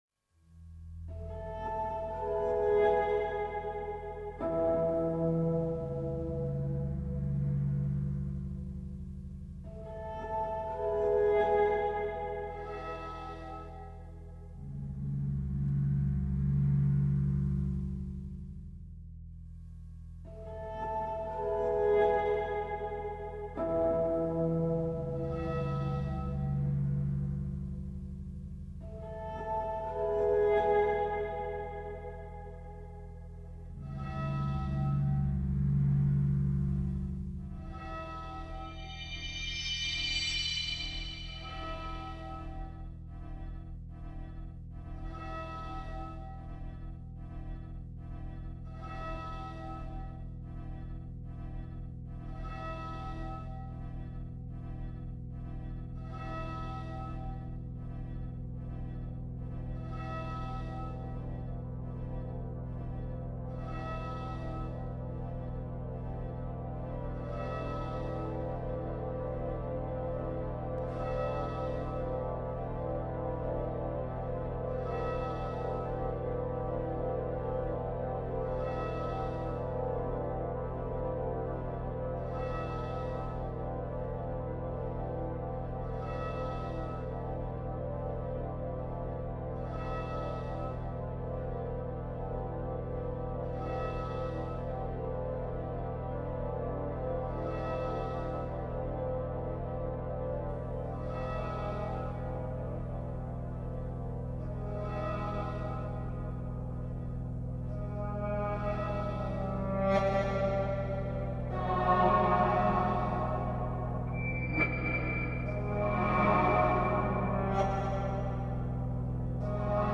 Cartmel Priory bells reimagined